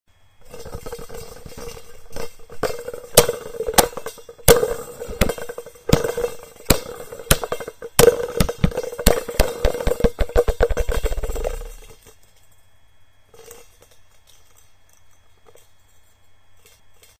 Esto debido, a que la bola como tiene un cascabel adentro, irá sonando mientras hace el rebote y va en dirección al estudiante.
Escuchar la bola de baloncesto con cascabel rebotando.
bolabaloncestosonora.mp3